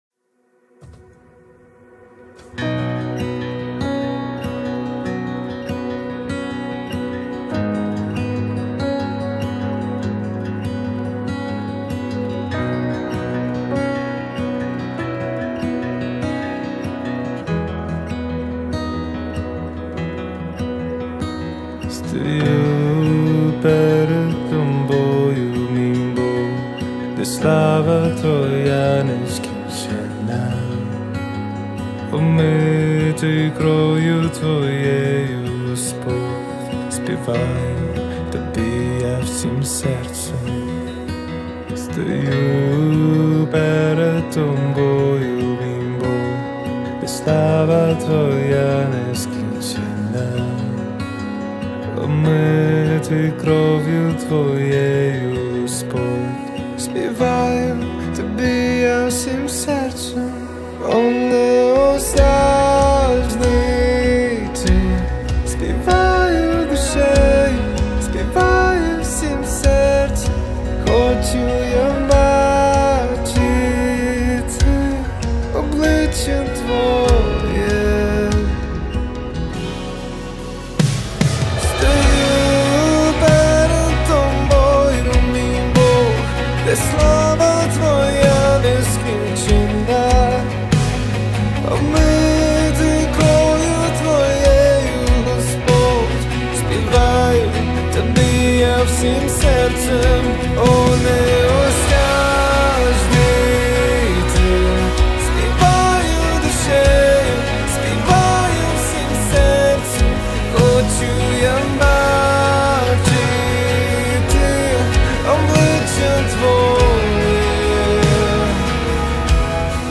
169 просмотров 119 прослушиваний 3 скачивания BPM: 140